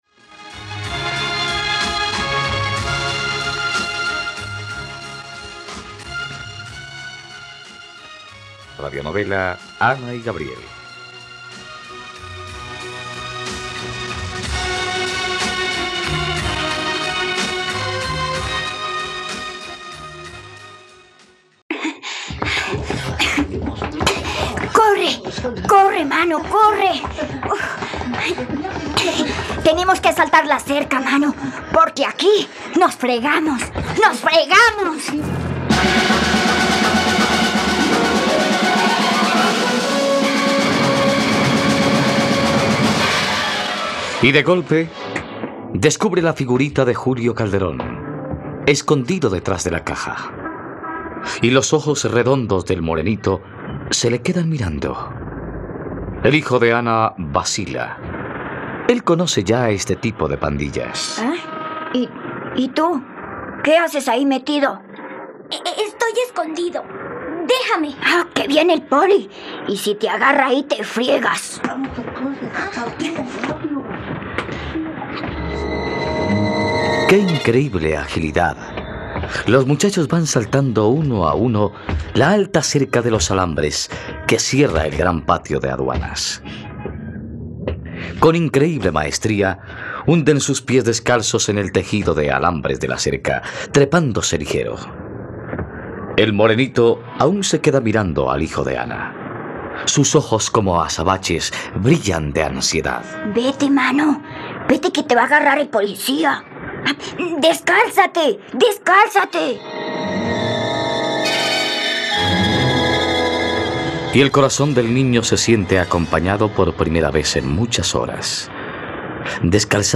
..Radionovela. Escucha ahora el capítulo 88 de la historia de amor de Ana y Gabriel en la plataforma de streaming de los colombianos: RTVCPlay.